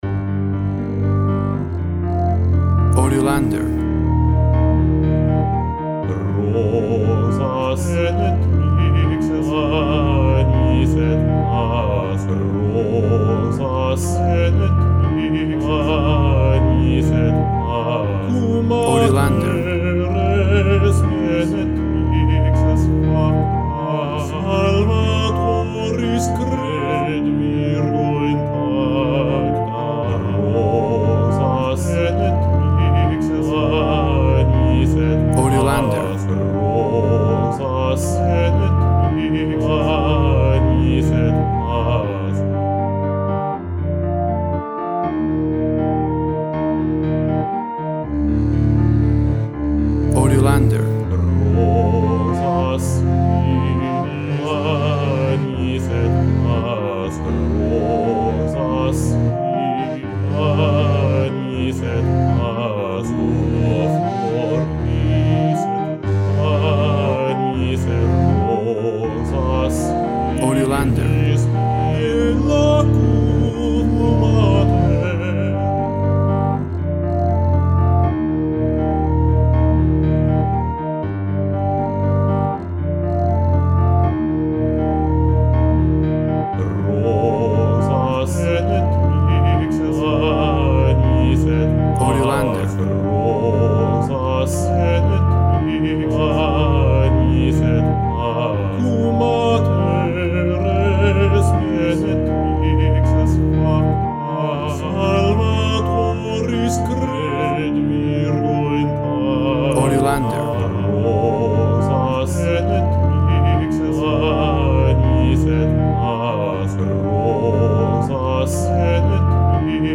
WAV Sample Rate 24-Bit Stereo, 44.1 kHz
Tempo (BPM) 120